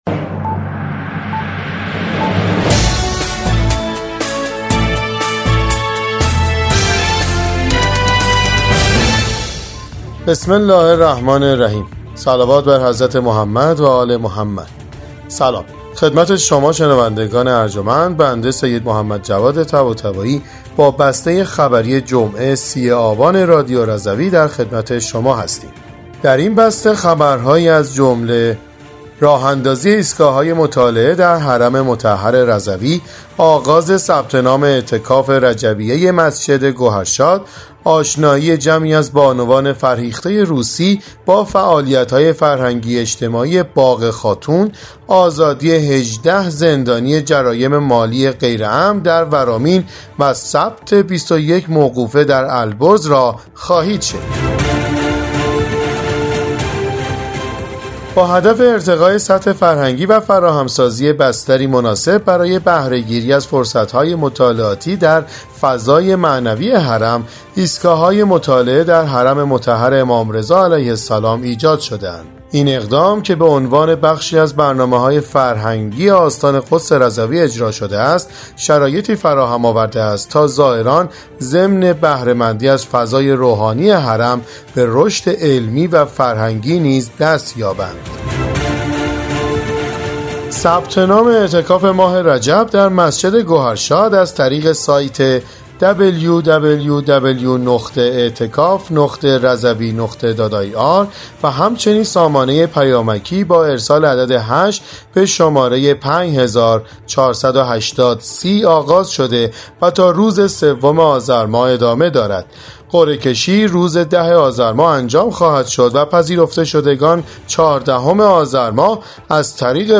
بسته خبری ۳۰ آبان ۱۴۰۴ رادیو رضوی؛